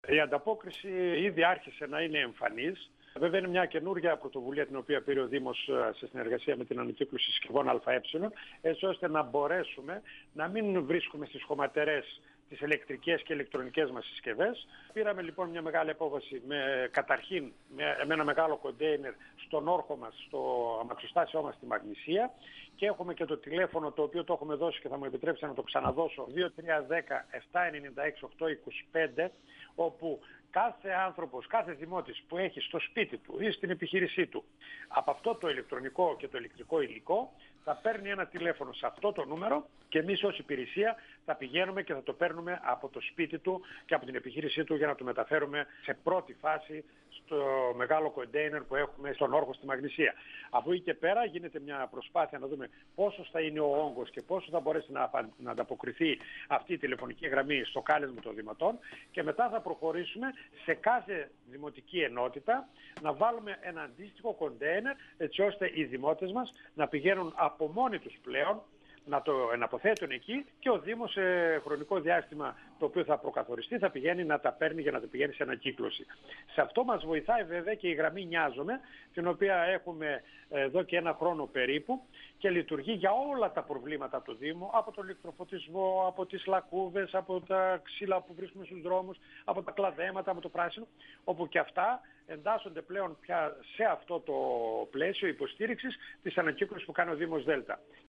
Ο δήμαρχος Δέλτα Μίμης Φωτόπουλος,  στον 102FM του Ρ.Σ.Μ. της ΕΡΤ3
Συνέντευξη